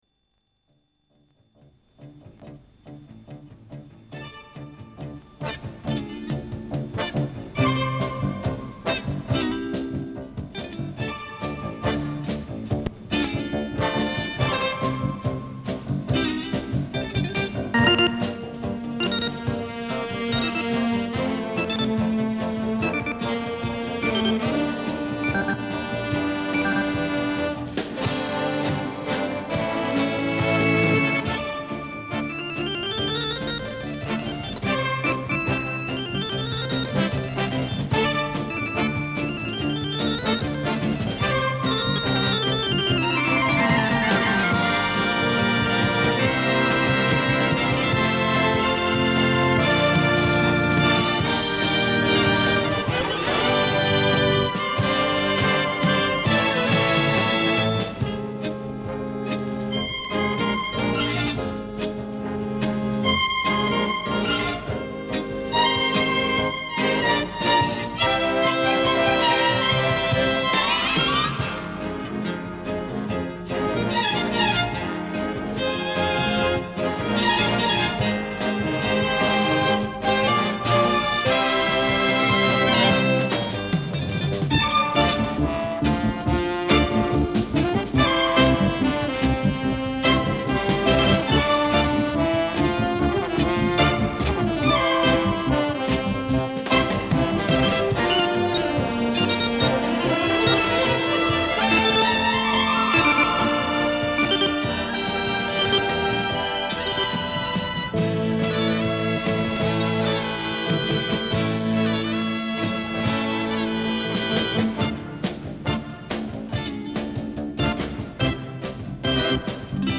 инструментальную пьесу
Есть ещё такой вариант, записанный с катушки ещё в 90-е.
Качество тоже так себе: